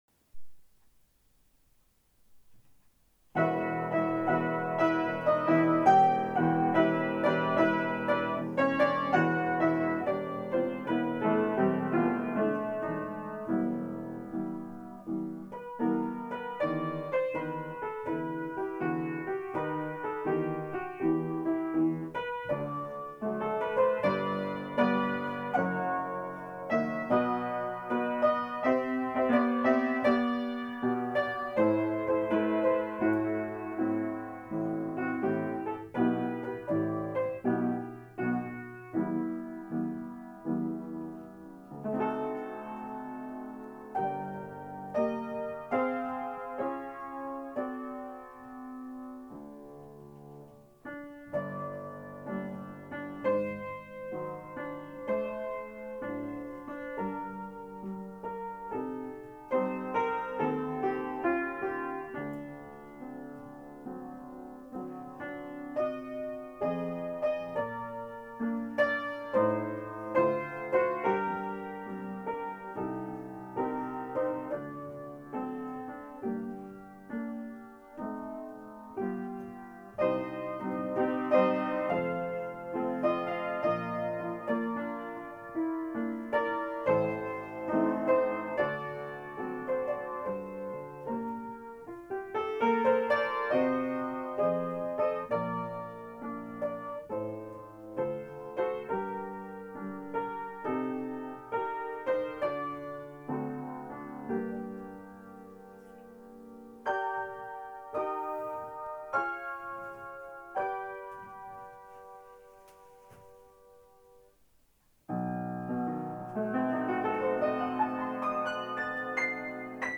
В рамках проекта художественного отдела «Музыка Великой Отечественной войны», предлагаю вашему вниманию мою инструментальную импровизацию на темы военных песен. Однако, это не просто музыкальный опус, а викторина-загадка, в которой прозвучат 7 фрагментов известных песен того времени.